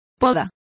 Complete with pronunciation of the translation of prunings.